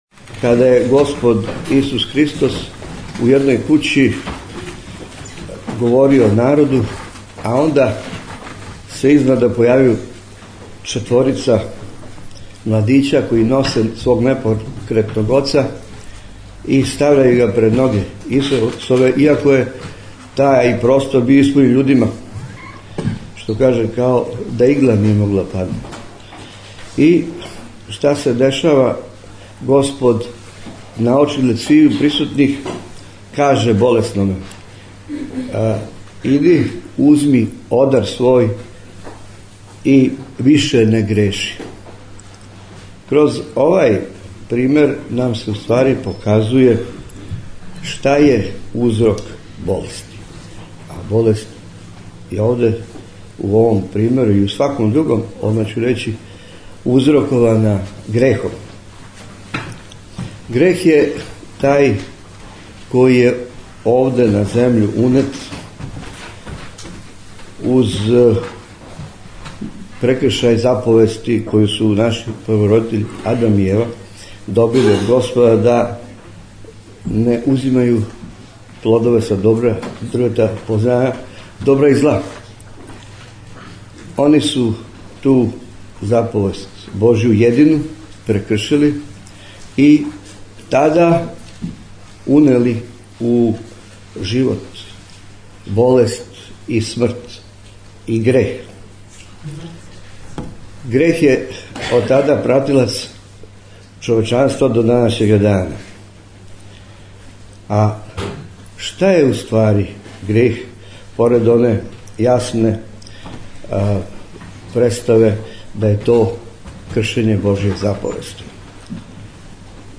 у пуној сали Општине Вождовац
У пуној сали ГО Вождовац